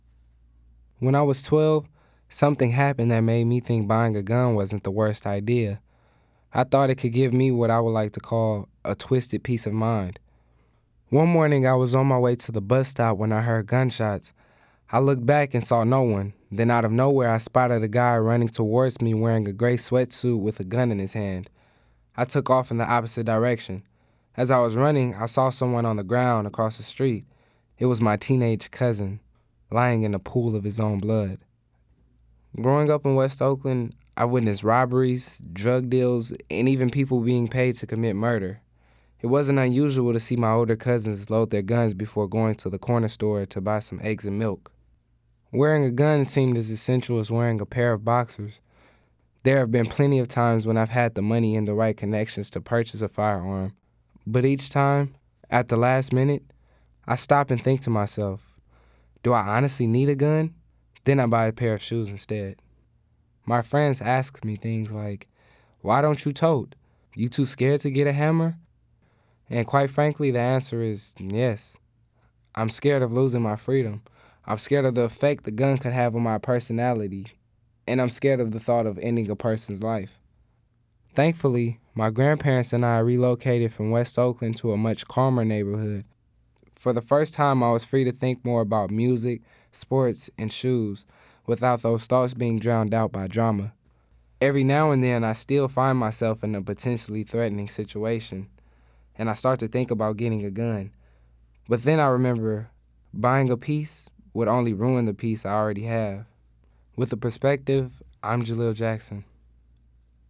Illustrated Commentary: A Piece Of Mind